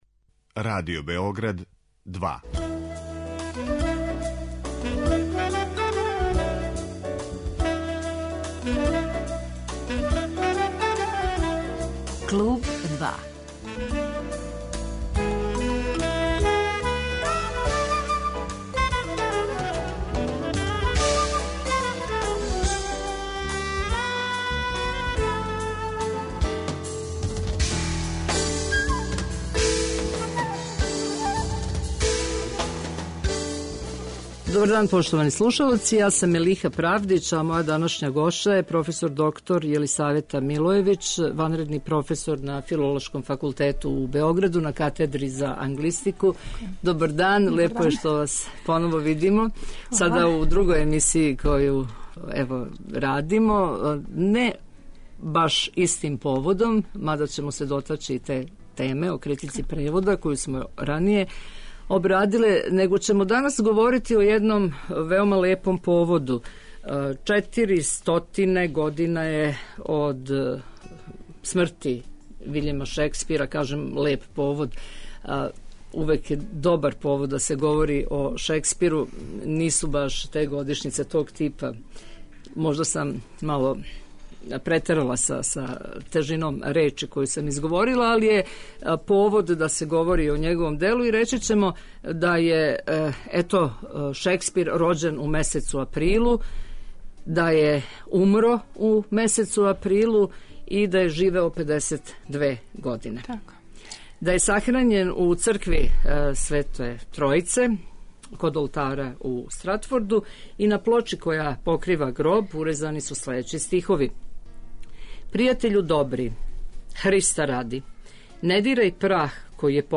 Разговор са проф.